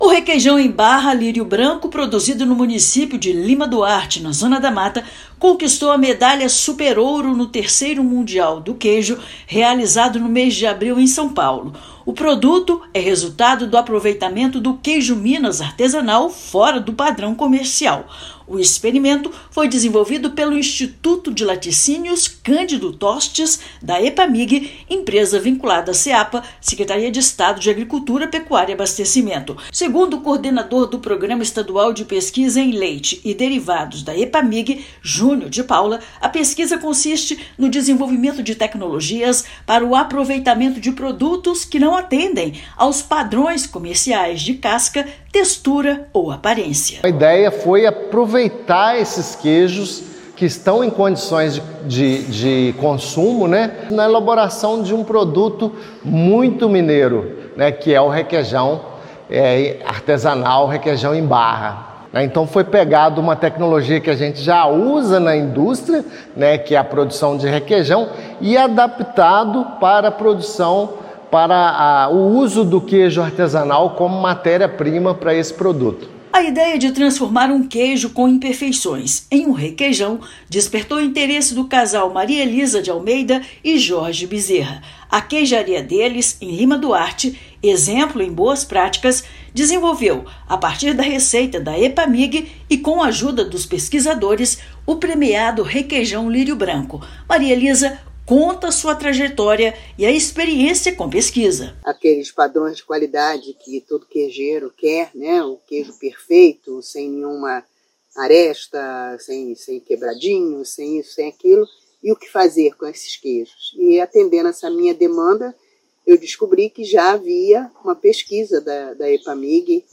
Técnica foi desenvolvida pela Empresa de Pesquisa Agropecuária do Governo de Minas; produto de Lima Duarte já conquistou medalha Super Ouro no 3º Mundial do Queijo do Brasil. Ouça matéria de rádio.